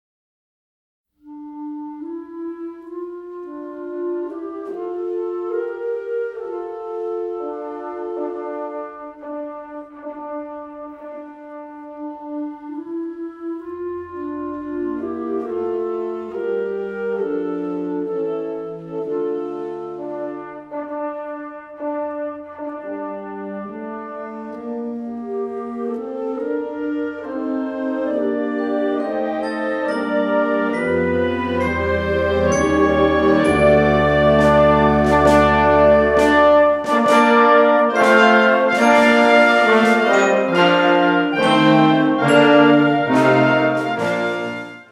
Catégorie Harmonie/Fanfare/Brass-band
Sous-catégorie Ouvertures (œuvres originales)